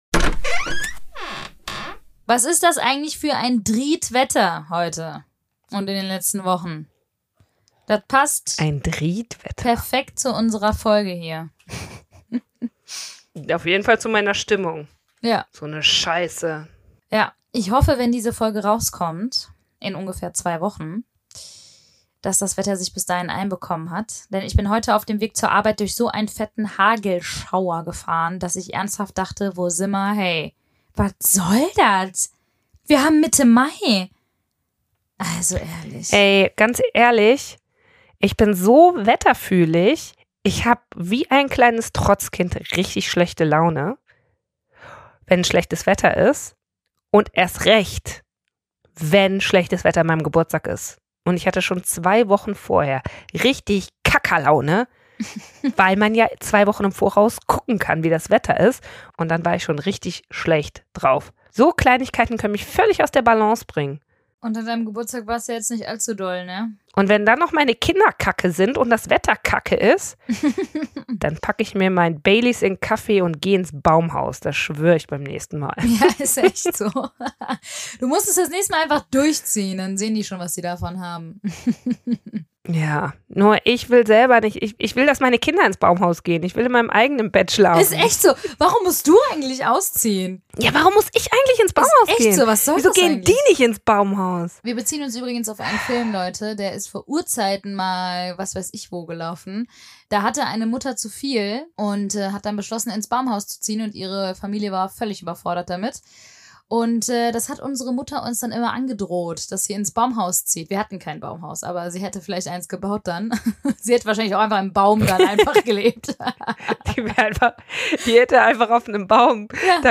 In der ersten Teil über Depression räumen die Schwestern mit einigen falschen Mythen rund um diese psychische Erkrankung auf.
Wir sprechen offen und locker über diese Themen - gegen Stigmatisierung und für Offenheit und Toleranz.